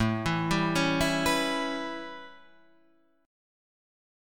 A9 chord